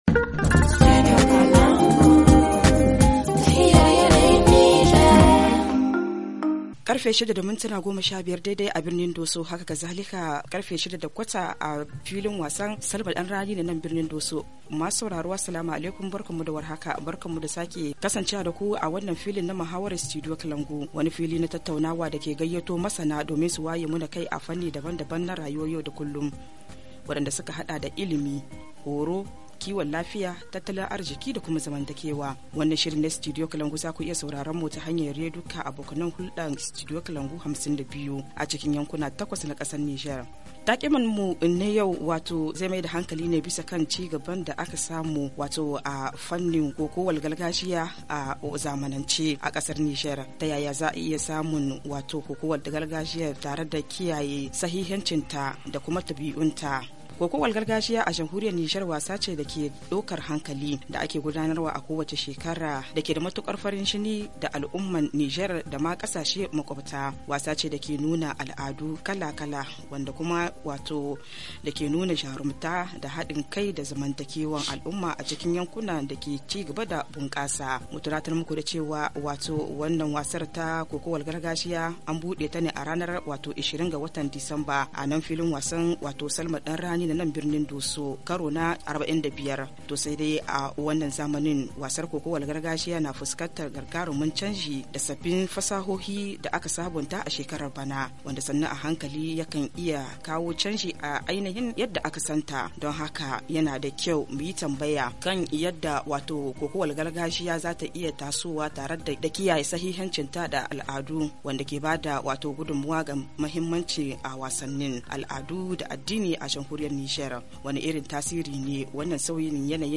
amatrice de la lutte traditionnelle HA Le forum en haoussa Télécharger le forum ici.